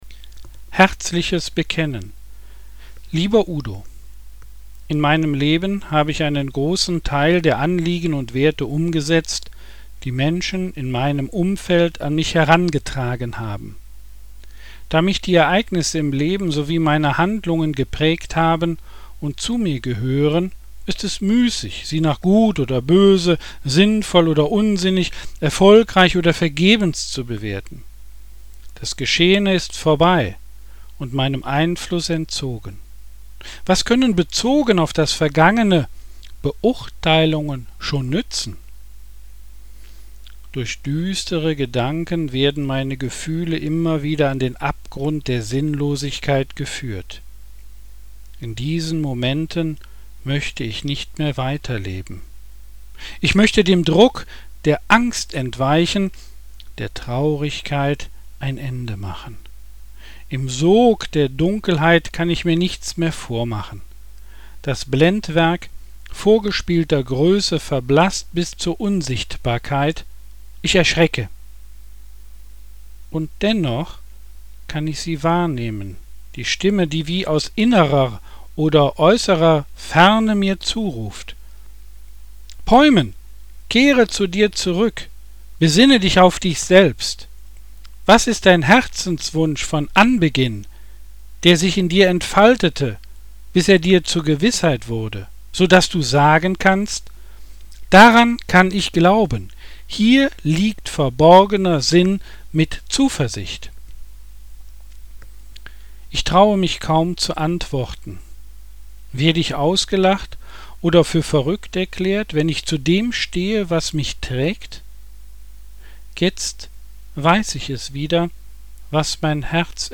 Textlesung